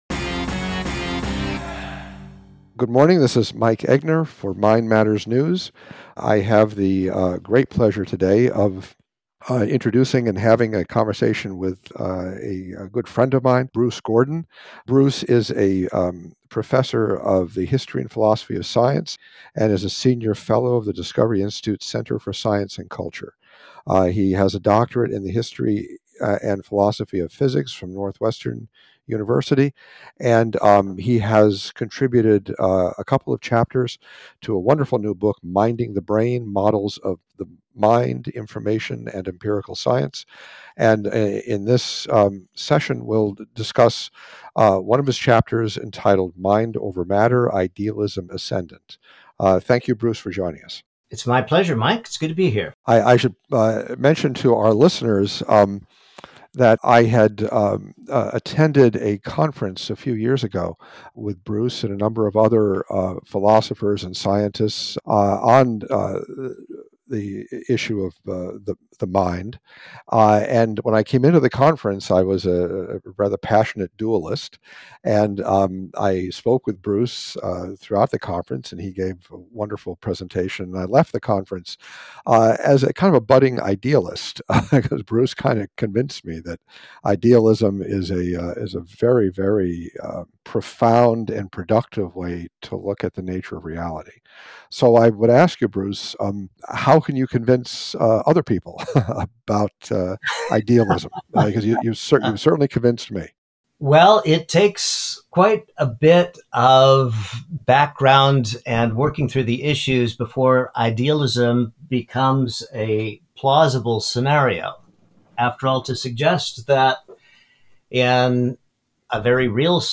The conversation also highlights the social pressures and biases within the academic community that contribute to the popularity of physicalism and the reluctance to consider alternative perspectives.